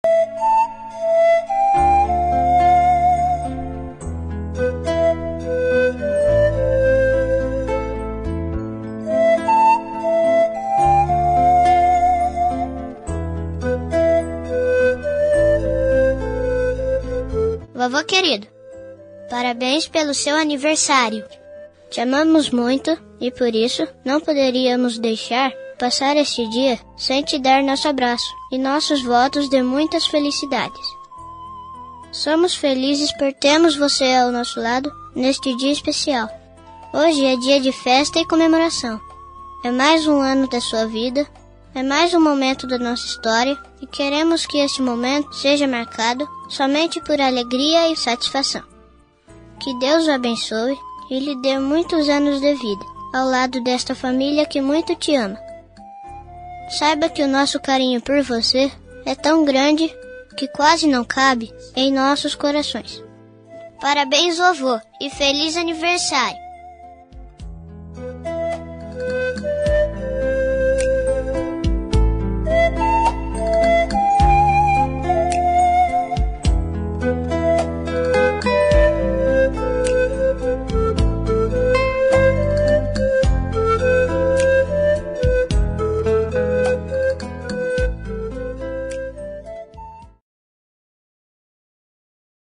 Aniversário Voz Infantil – Avô – Voz Masculina – Cód: 258265